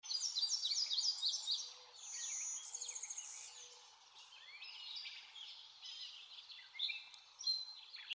Blue winged Warbler calls / sings sound effects free download
Blue-winged Warbler calls / sings its buzzy song